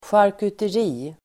Ladda ner uttalet
Uttal: [sjarkuter'i:]
charkuteri.mp3